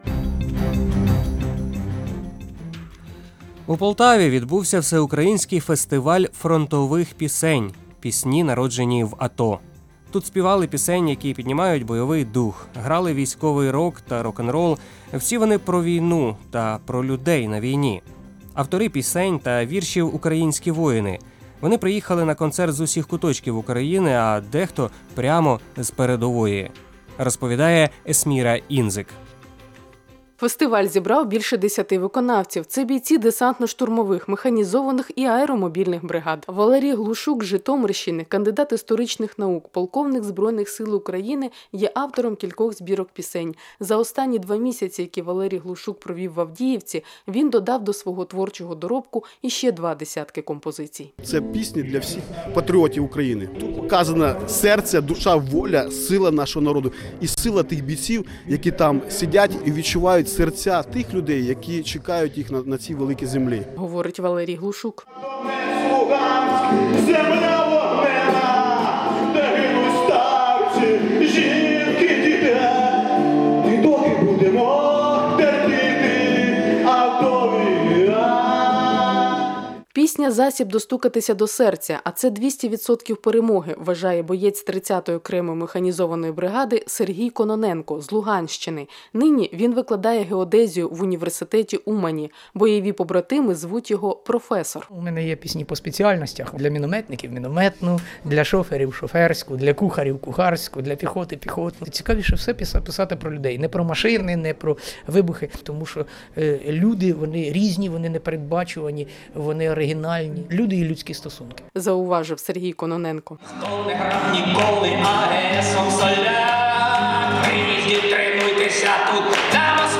«Пісні, народжені в АТО» – фестиваль фронтових пісень відбувся у Полтаві